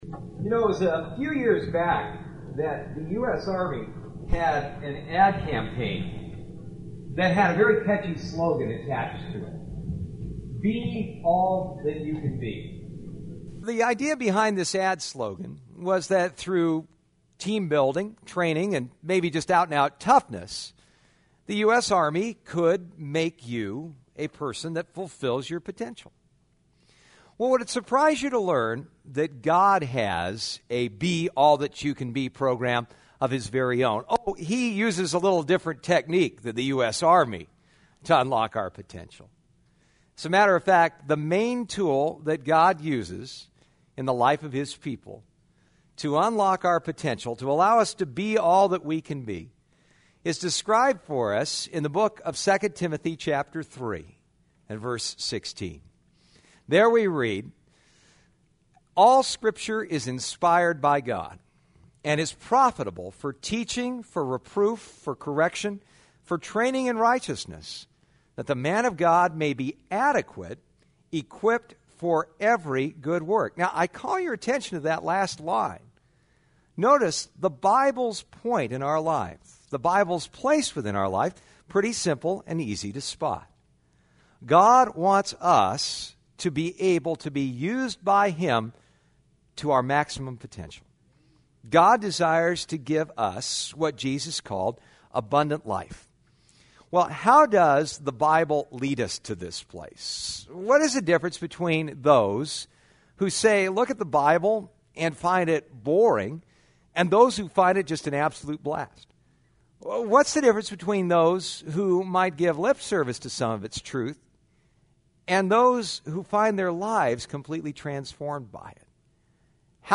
Psalms 2008 Passage: Psalm 19:10-14 Service Type: Sunday Morning « Triumph in Times of Trouble Creation